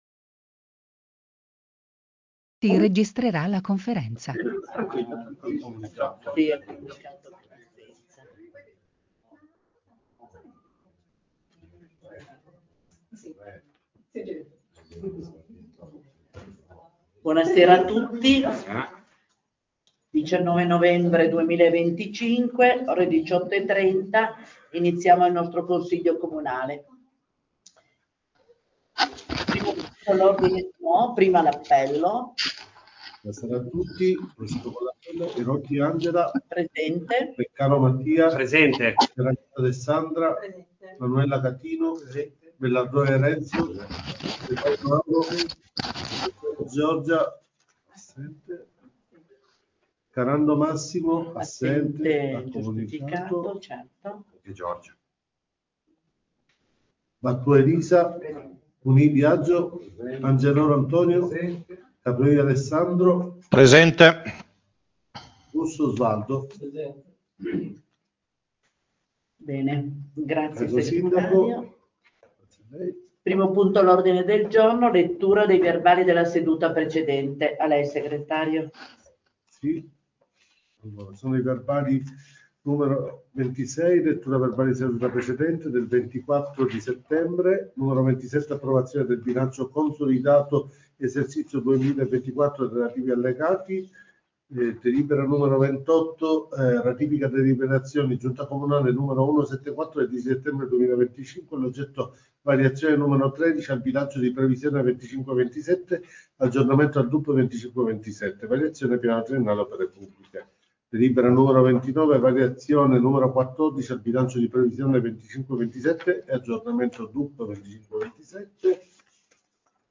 Comune di Santhià - Registrazioni audio Consiglio Comunale - Registrazione seduta Consiglio Comunale 19/11/2025